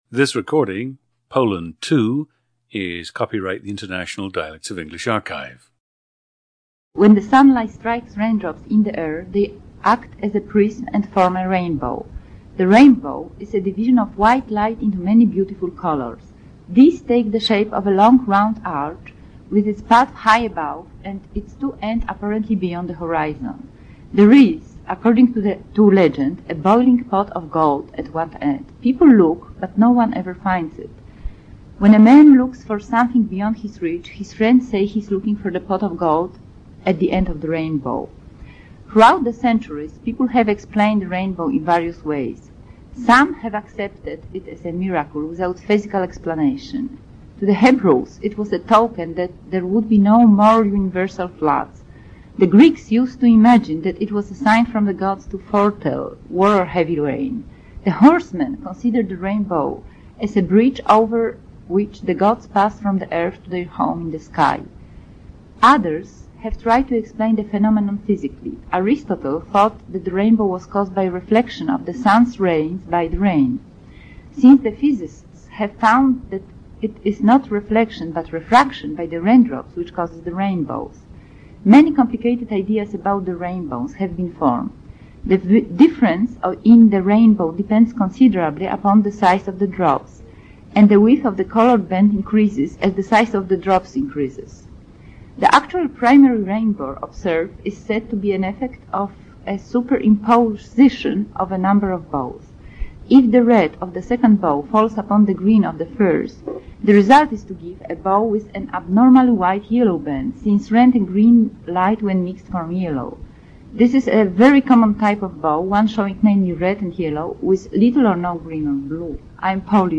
GENDER: female
(Despite that time in Cairo, the subject has a textbook Polish accent.)
The recordings average four minutes in length and feature both the reading of one of two standard passages, and some unscripted speech.